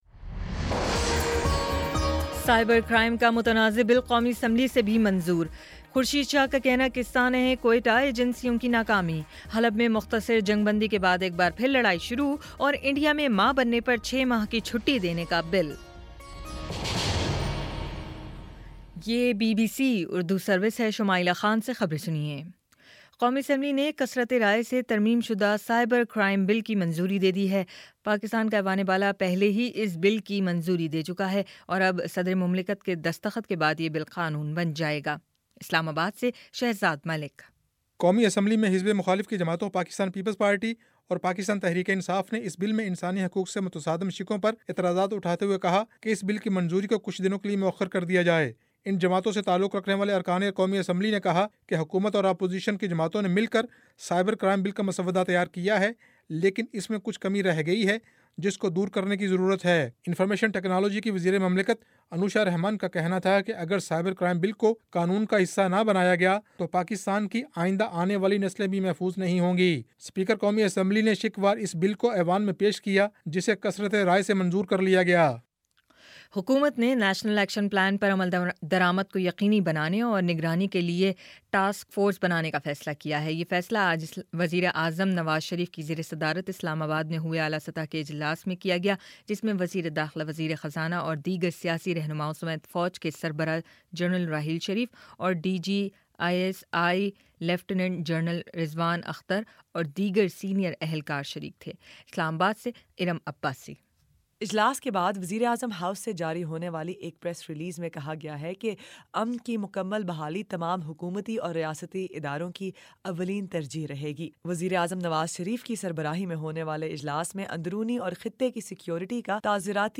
اگست 11 : شام چھ بجے کا نیوز بُلیٹن